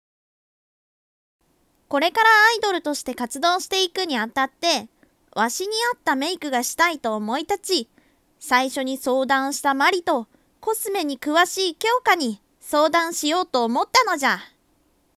声劇リテイク